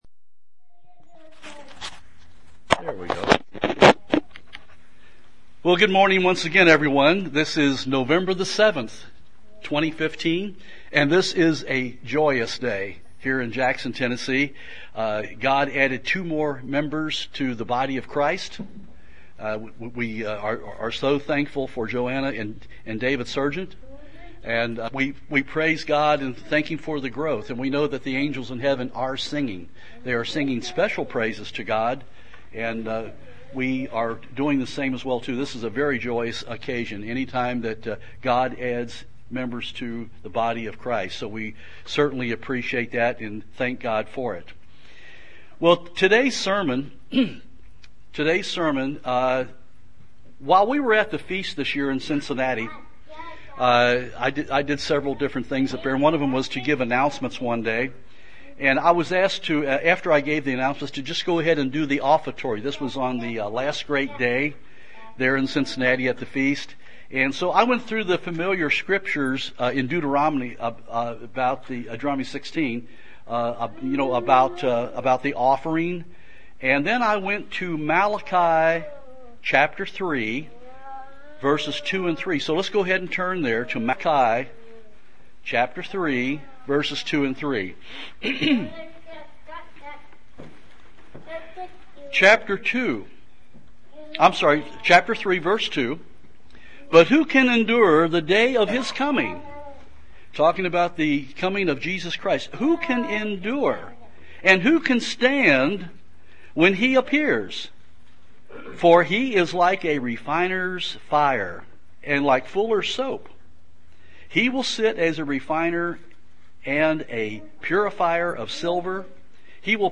He wants us to give ourselves to Him every day. This sermon examines ways that we need to give ourselves to God everyday.